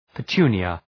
Προφορά
{pə’tu:nıə}